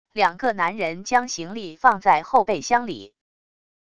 两个男人将行李放在后备箱里wav音频